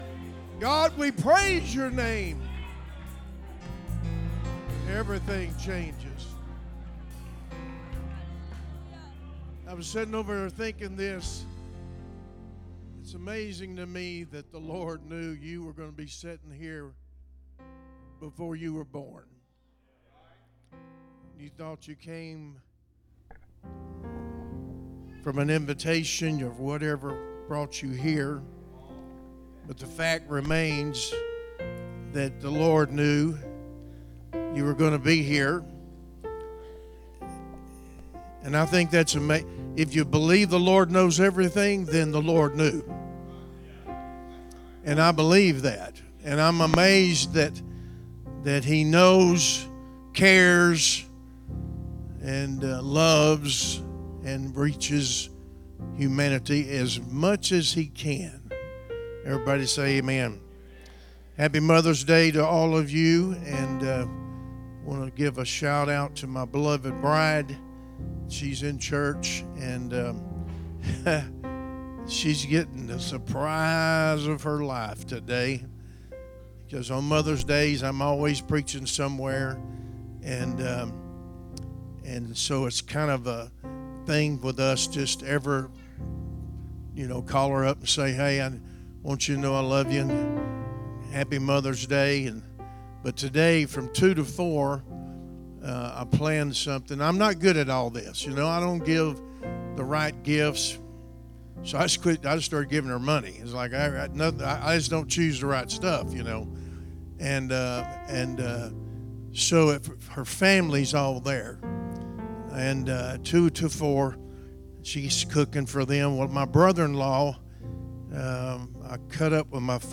Mother’s Day Service